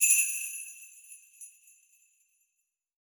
Sleigh Bells (2).wav